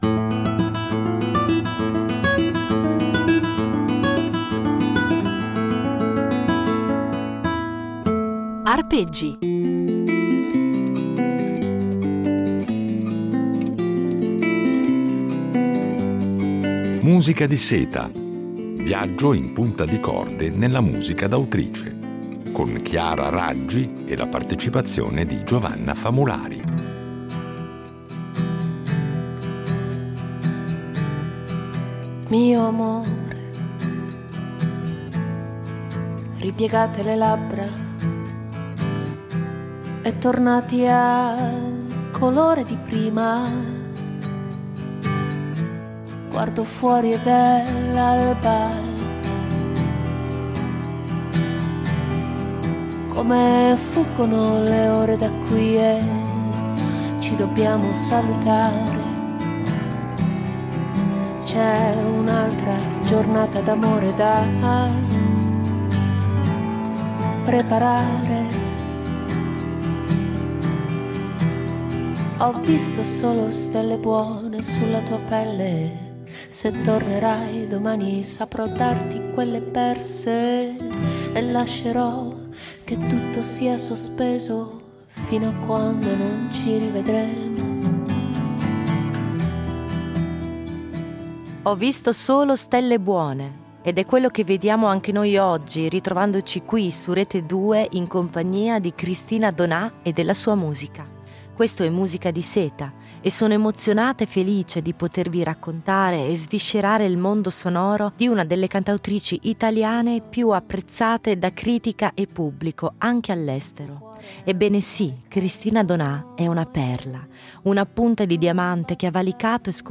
Una serie di Arpeggi impreziosita dalle riletture originali di un duo